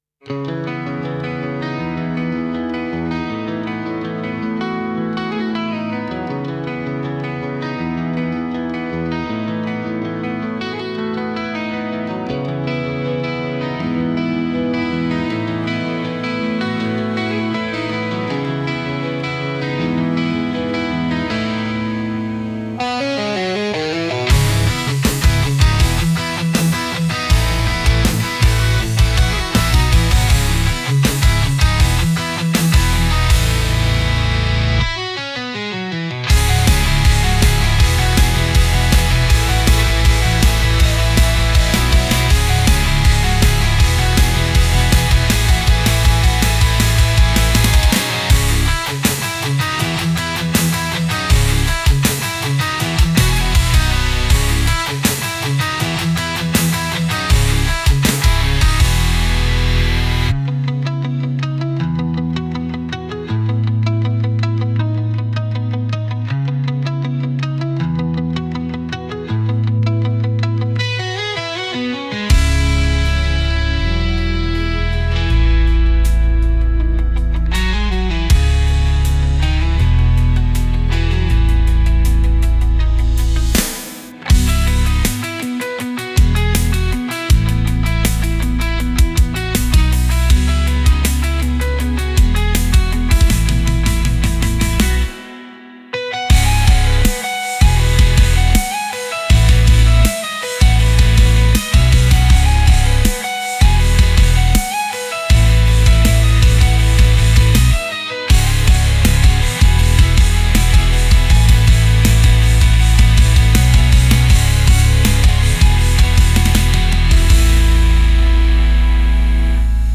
Genre: Emotional Mood: Raw Editor's Choice